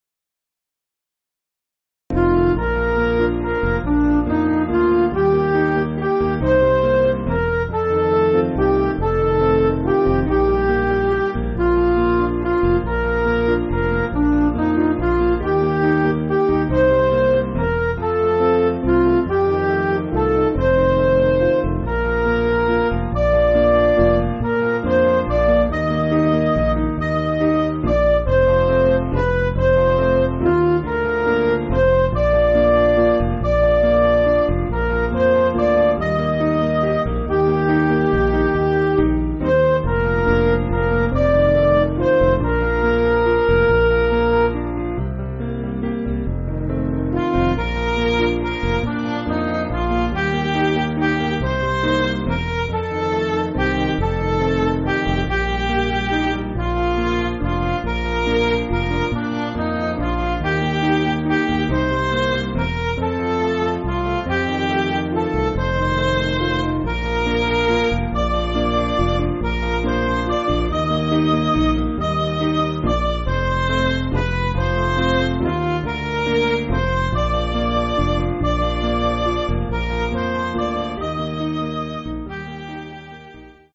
Piano & Instrumental
(CM)   4/Bb